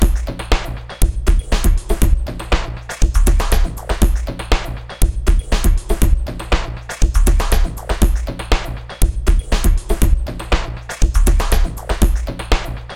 To make things more interesting, we prepared a little more complex programme material.
Hint: take a close listening to highs, and their envelope:
Only filter type was changed.
LP_drumloop_highs_B.m4a